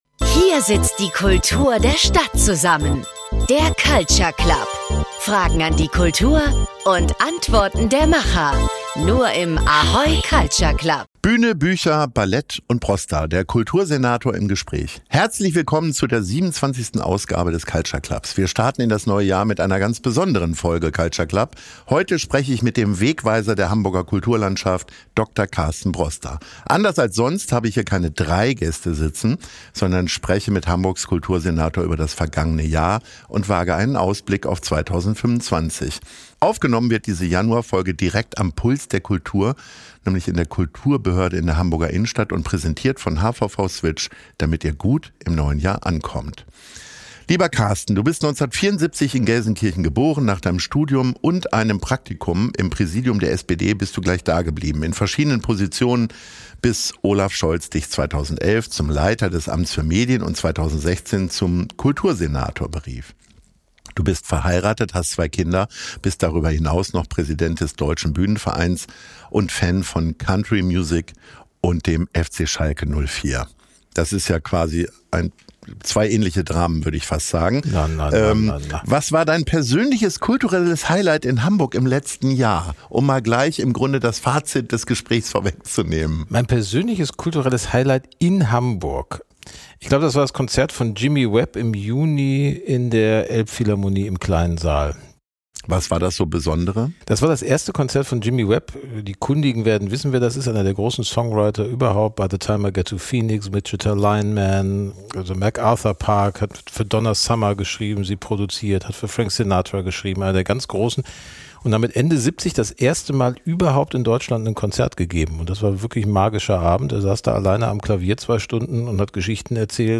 Bühne, Bücher, Ballet & Brosda – Der Kultursenator im Gespräch ~ Culture Club - Der Kulturtalk bei ahoy, präsentiert von hvv switch Podcast
Aufgenommen wurde diese Januar-Folge direkt am Puls der Kultur, der Kulturbehörde in der Hamburger Innenstadt und präsentiert von HVV Switch, damit ihr gut im neuen Jahr ankommt.